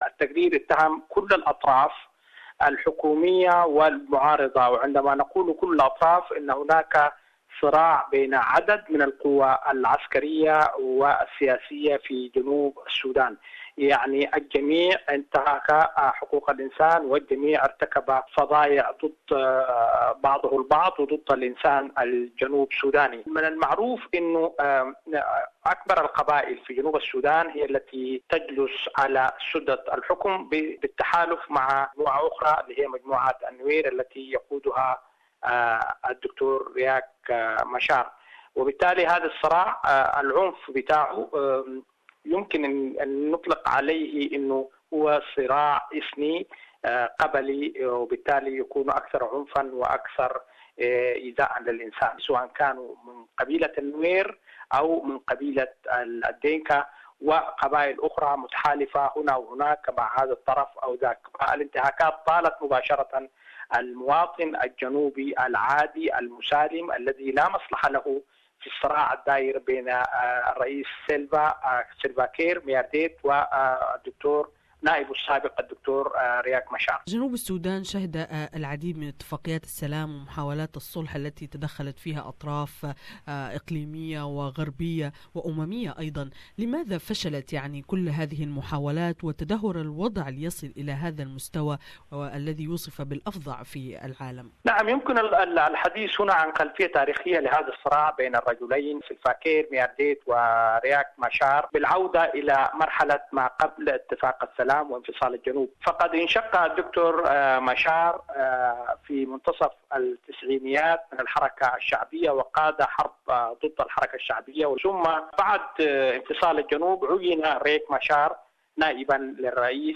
A United Nations report says South Sudan's government operated what it calls a "scorched-earth policy" of deliberate rape, pillage and killing of civilians during the civil war in 2015. The U-N human-rights office says crimes against humanity continued right through until last year. More in this interview